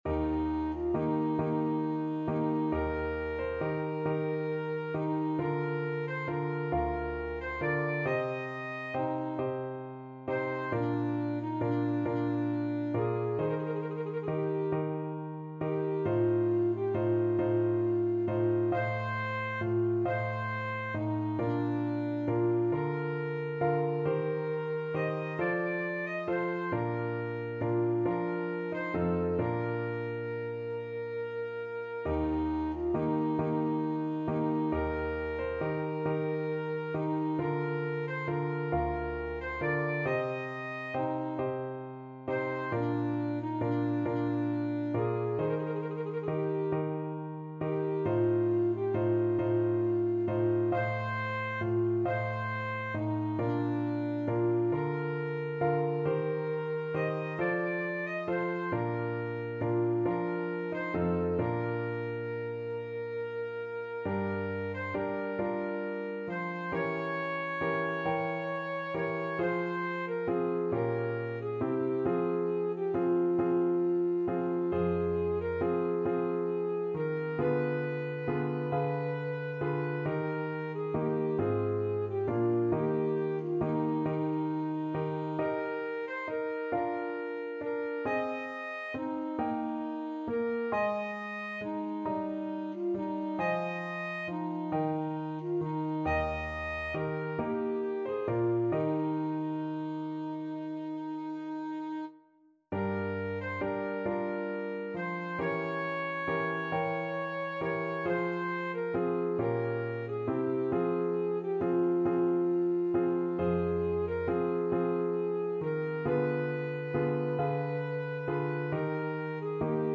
Classical Vivaldi, Antonio Concerto for Flute and String Orchestra 'Il Gardellino', Op.10, No.3, 2nd movement Alto Saxophone version
Alto Saxophone
Eb major (Sounding Pitch) C major (Alto Saxophone in Eb) (View more Eb major Music for Saxophone )
12/8 (View more 12/8 Music)
II: Larghetto cantabile .=45
D5-Eb6
Classical (View more Classical Saxophone Music)
vivaldi_op10_3_ASAX.mp3